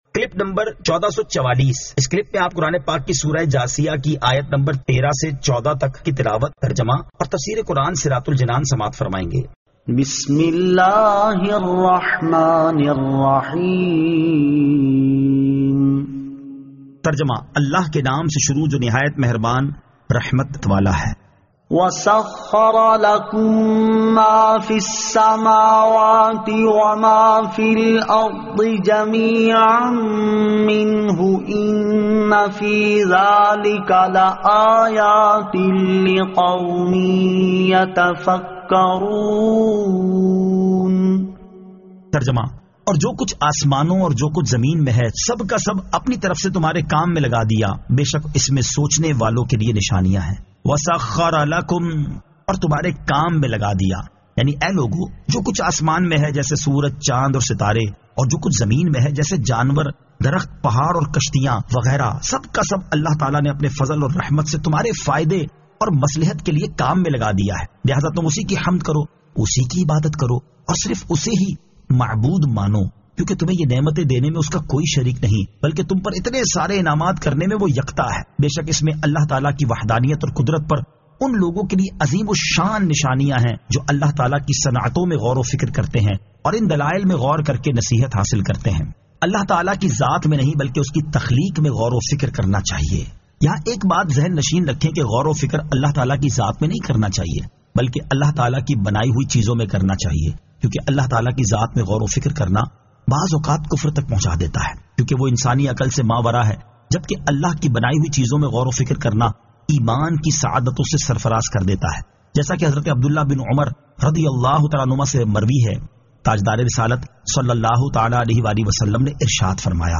Surah Al-Jathiyah 13 To 14 Tilawat , Tarjama , Tafseer